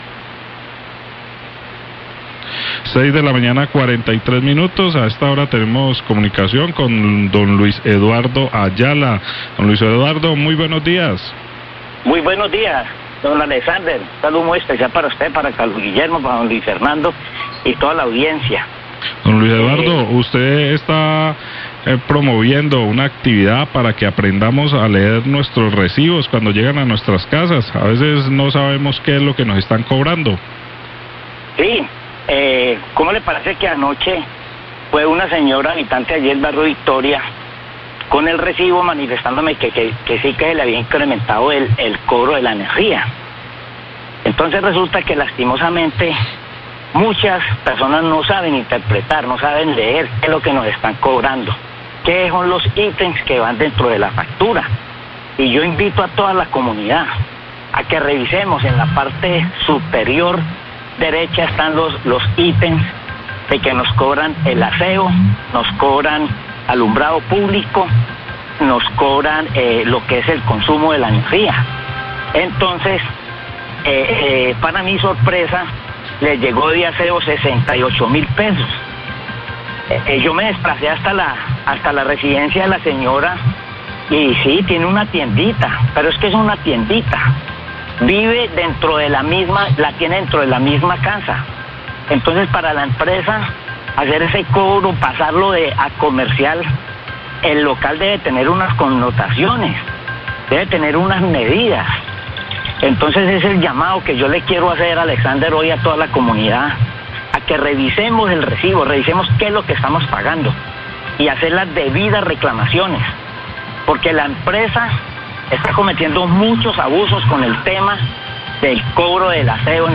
Radio
El concejal Ayala de Tuluá afirma que es importante capacitar a la comunidad sobre la forma en que se lee la factura del servicio de energía para evitar confusiones. Esto debido a las quejas permanentes por los cobros exagerados de la empresa del servicio de aseo que incluye el cobro en la factura de la energía.